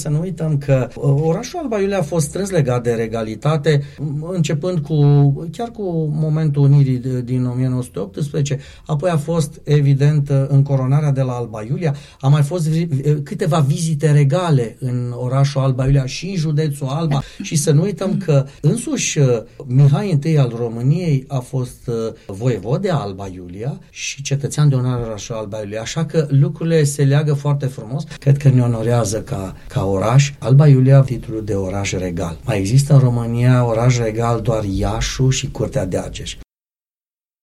Despre acest moment a vorbit la Unirea FM primarul municipiului Alba Iulia, Gabriel Pleșa